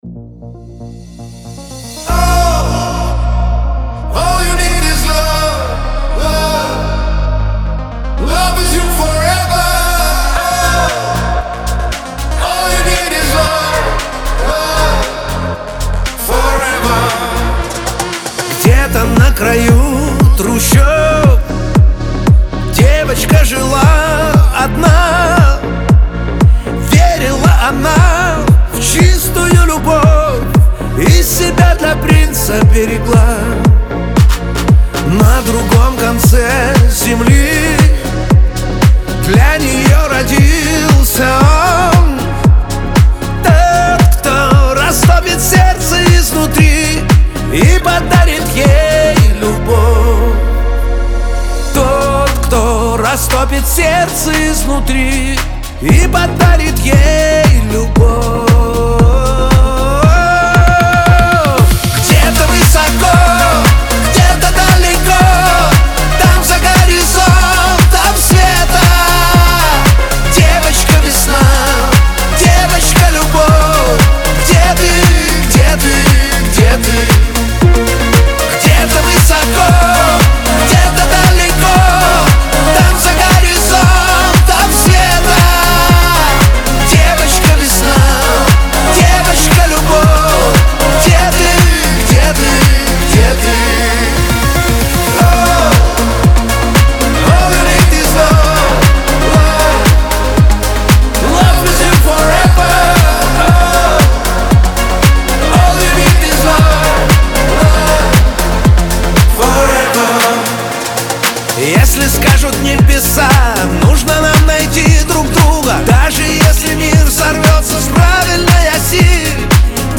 эстрада
диско